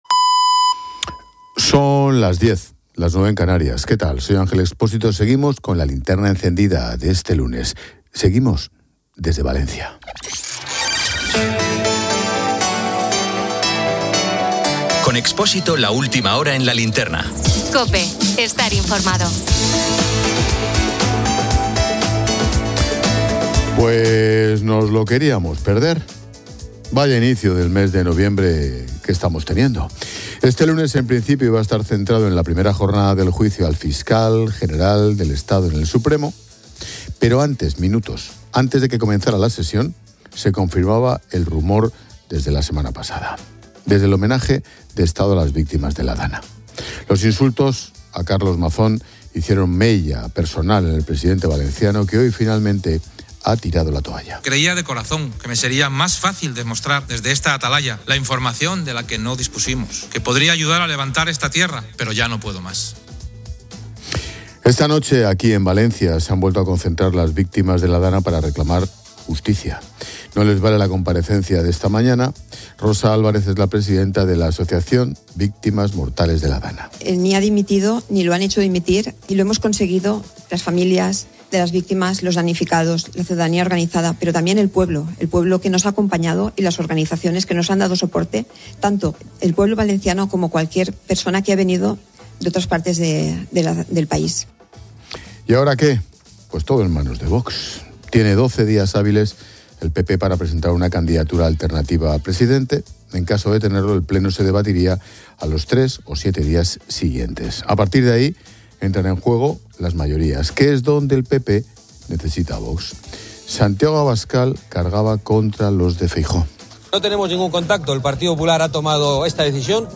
La tertulia debate la inaudita situación del Fiscal General y la ejemplaridad pública.